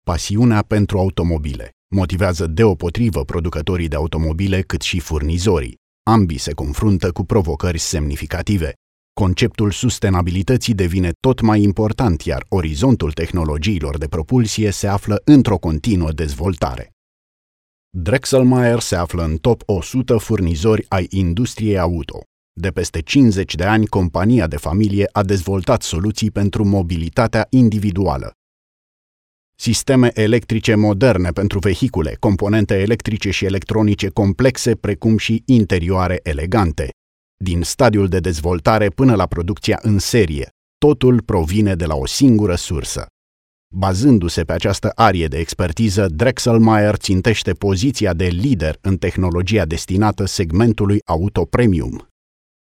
Experienced Voice Over, Romanian native
Sprechprobe: Industrie (Muttersprache):